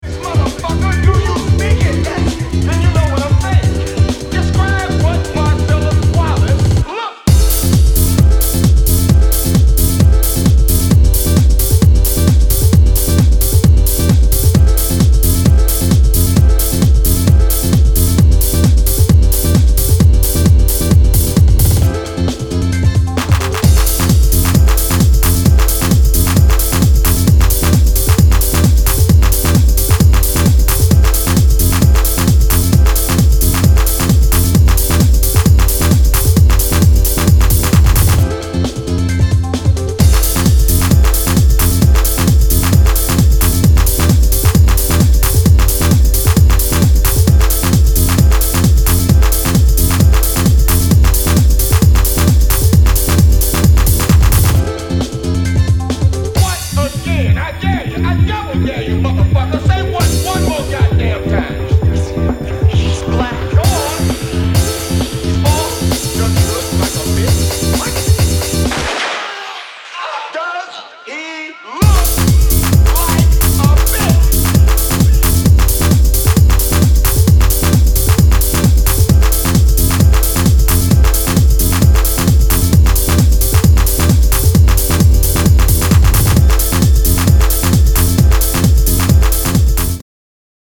いずれも、90s前半のイタリアン・ハウスの情緒的な部分までもモダンなプロダクションできちんと汲み取った意欲作。